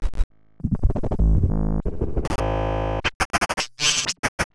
Glitch FX 09.wav